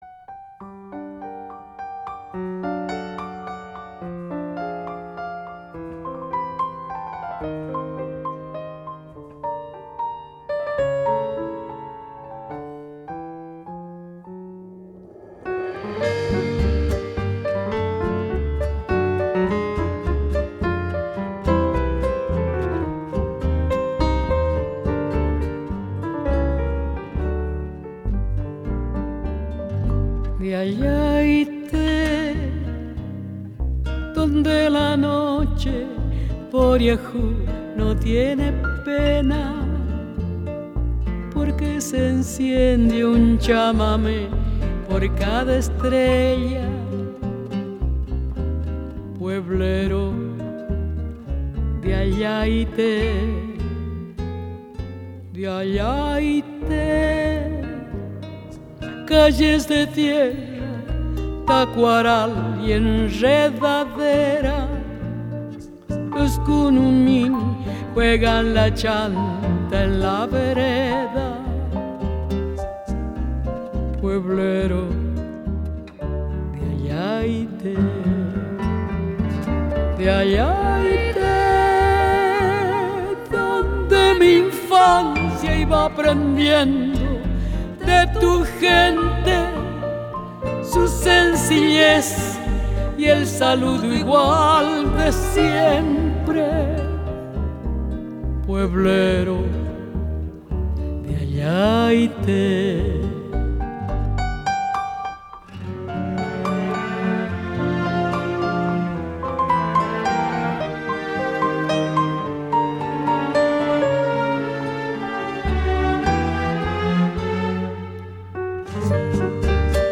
Жанр: Ethnic